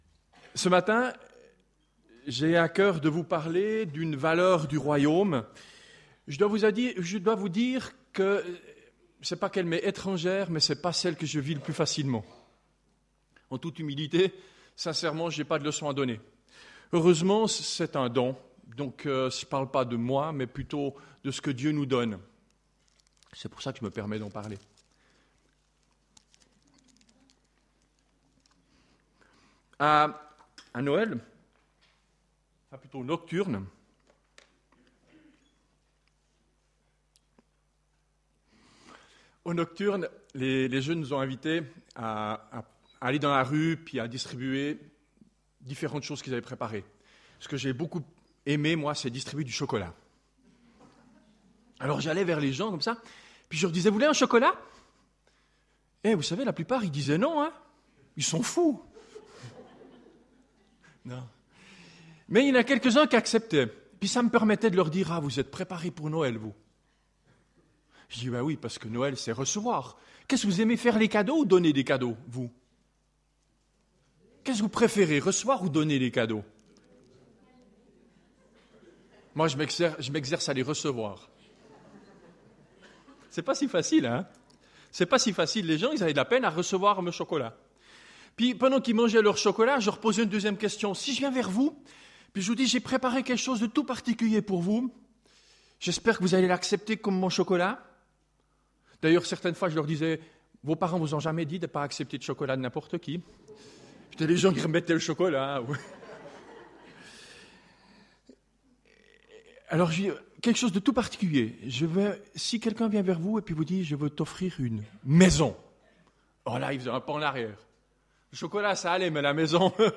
Culte du 10 janvier 2015